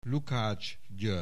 Aussprache Aussprache
LUKACSGYOERGY.wav